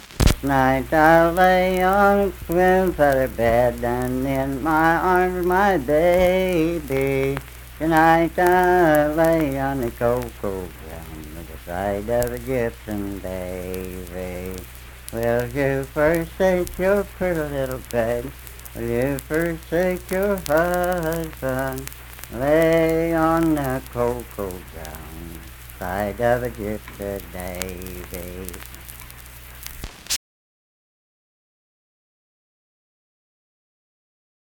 Unaccompanied vocal music performance
Voice (sung)
Clay County (W. Va.)